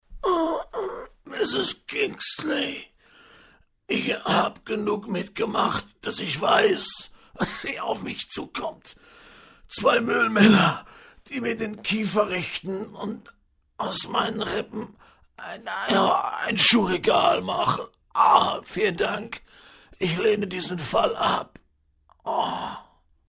Sprecher, Werbesprecher, Stationvoice